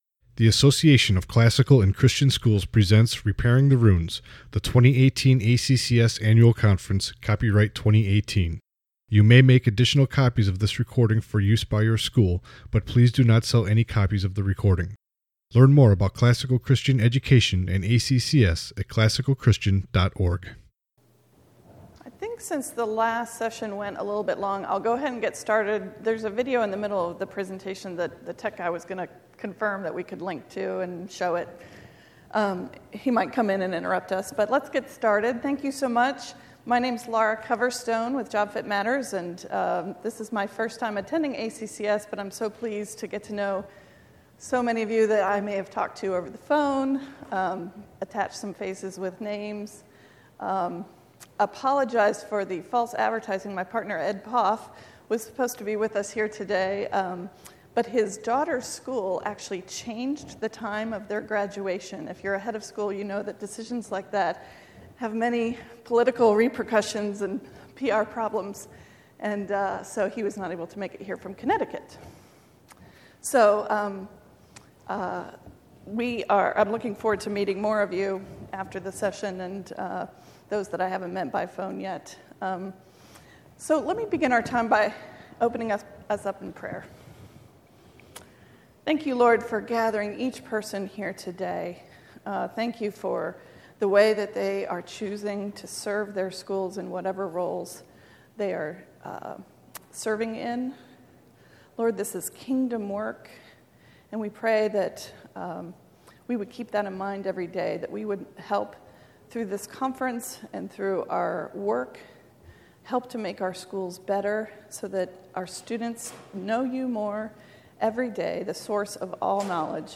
2018 Leaders Day Talk | 40:25 | Leadership & Strategic